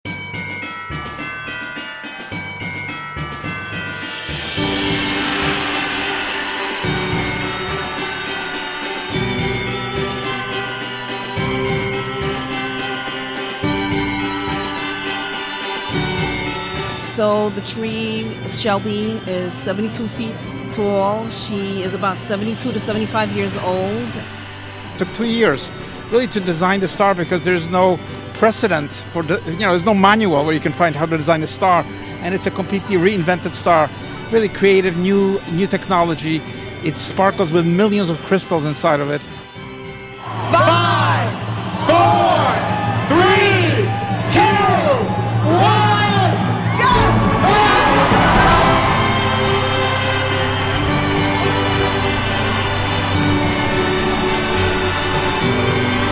Pentatonix, Kellie Pickler, Diana Ross, Tony Bennett & Diana Krall, Brett Eldredge, John Legend, Martina McBride and more took the stage to perform for the 86th Annual Rockefeller Center Christmas Tree Lighting in New York City. We were there for all the magic when the more than 50,000 multi-colored, energy efficient LED lights sparkled for the first time on the 72-foot-tall, 12-ton Norway Spruce from Wallkill, New York.